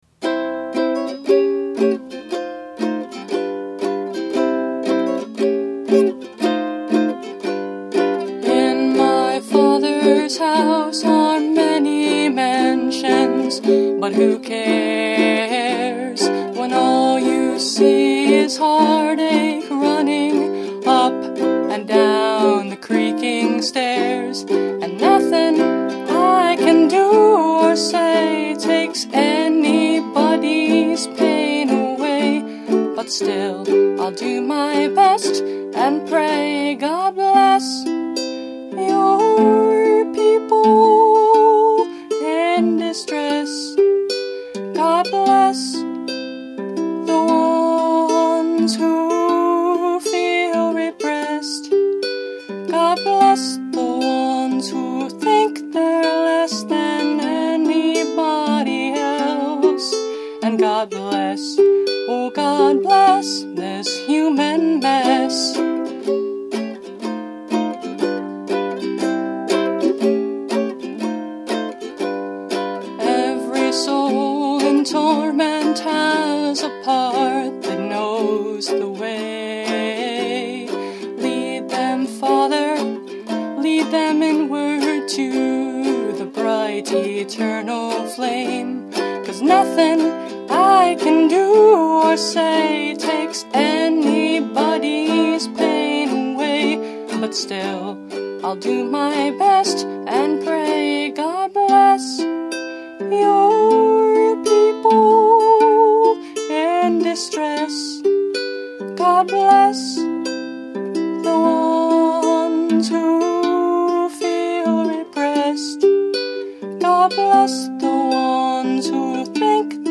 Instrument: Eventide – Mahogany Concert Ukulele